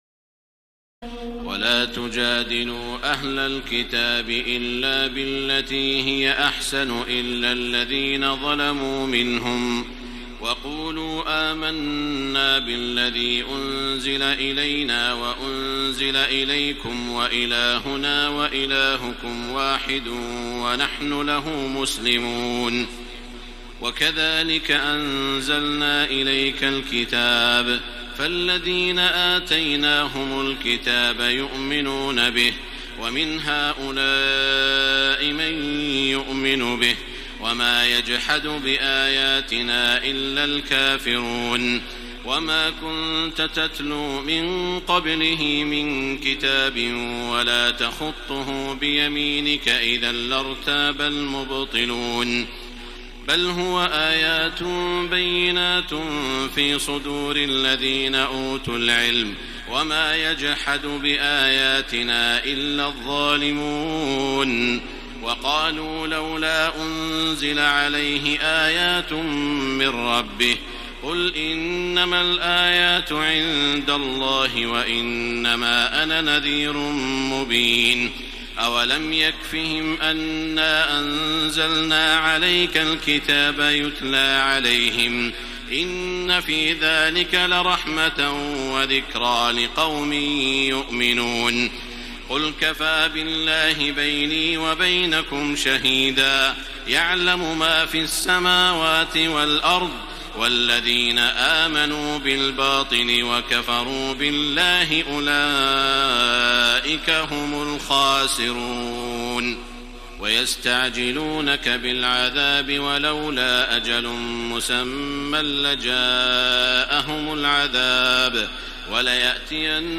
تراويح الليلة العشرون رمضان 1433هـ من سور العنكبوت (46-69) و الروم و لقمان (1-19) Taraweeh 20 st night Ramadan 1433H from Surah Al-Ankaboot and Ar-Room and Luqman > تراويح الحرم المكي عام 1433 🕋 > التراويح - تلاوات الحرمين